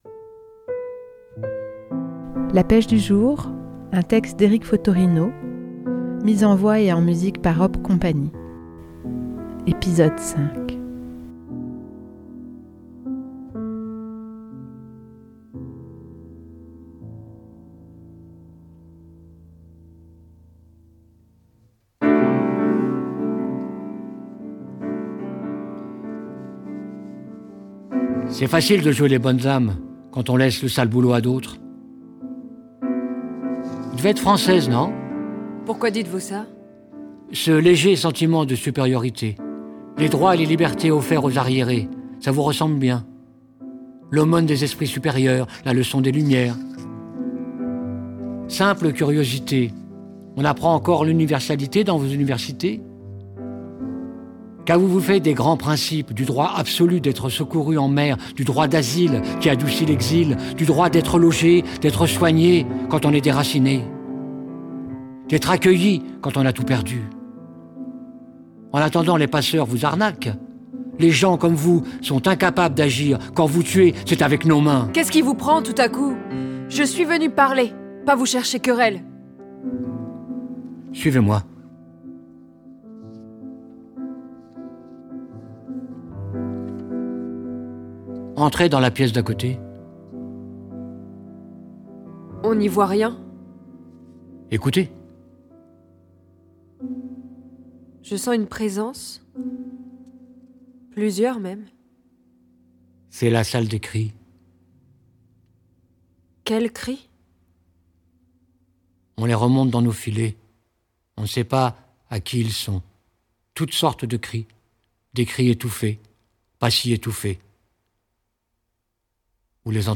Hop Cie met en scène et en musique le texte coup de poing d'Eric Fottorino "La pêche du jour".